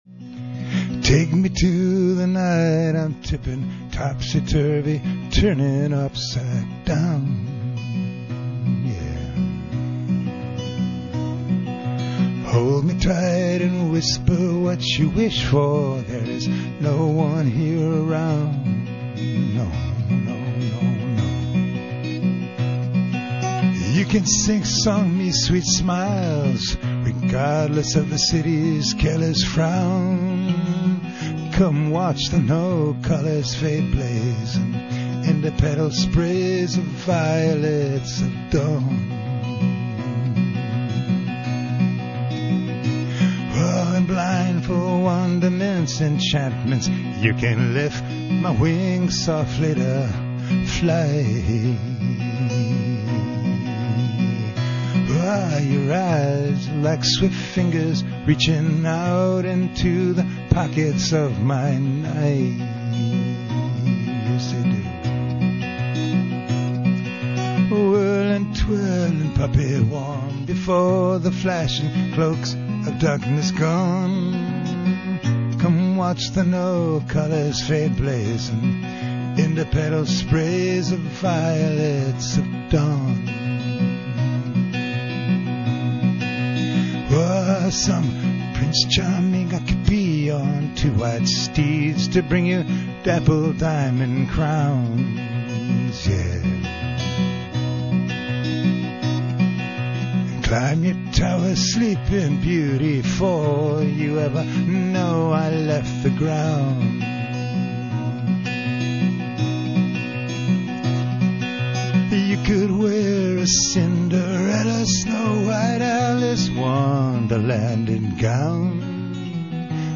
live songs (from radio)